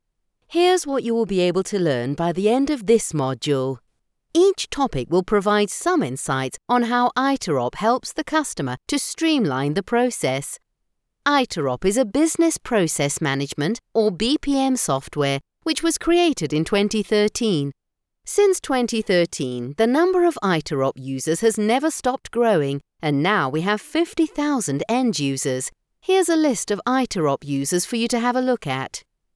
Mi acento inglés neutro funciona bien en los mercados internacionales.
Mi voz es natural y amigable, pero resonante y autoritaria. A mis clientes también les encanta mi voz seductora.
Micrófono Audio Technica AT2020